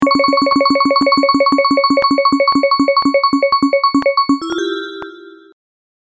digital_prize_wheel.mp3